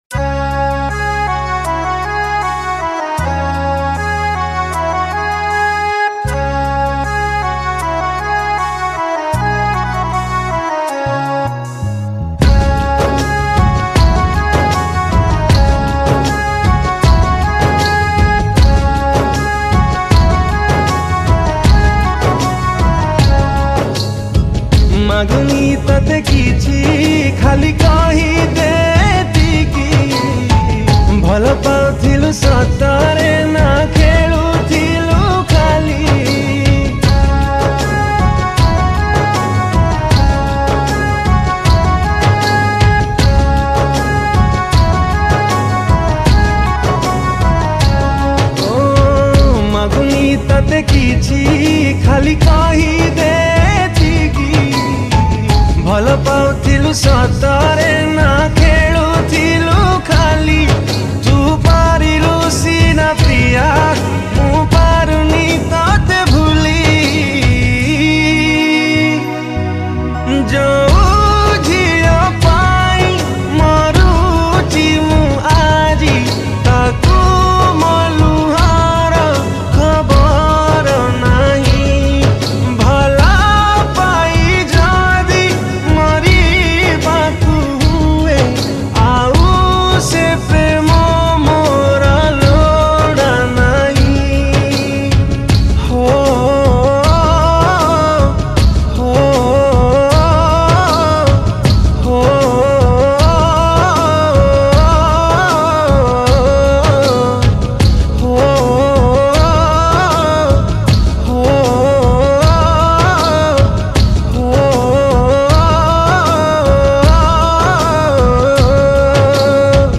Sad Romantic Songs